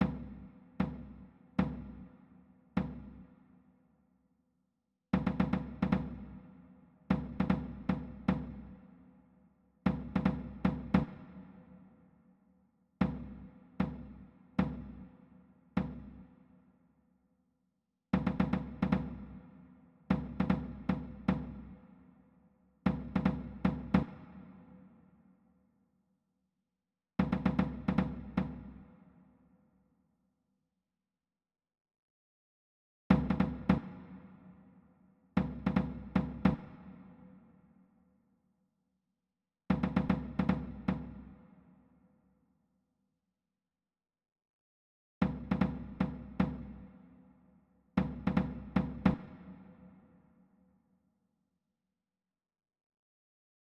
Tenor Audio